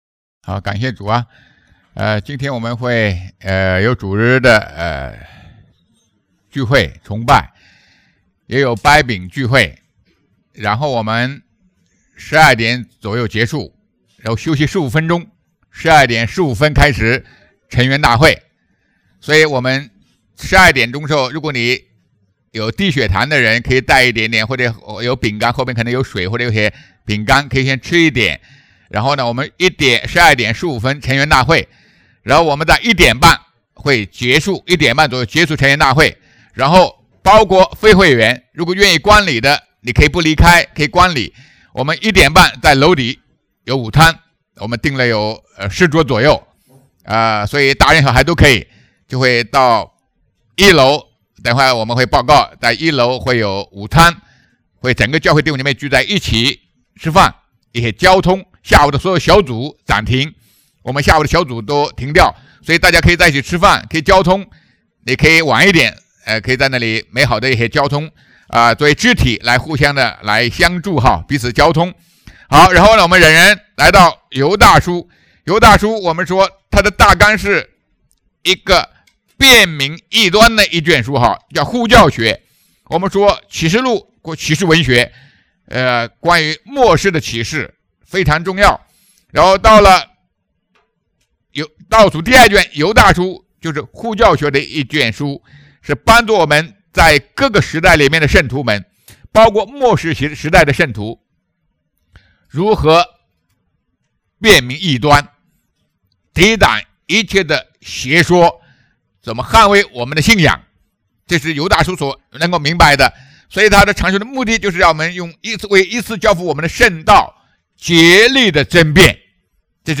谨防该隐的道路! 2025年1月5日 上午11:48 作者：admin 分类： 犹大书圣经讲道 阅读(1.6K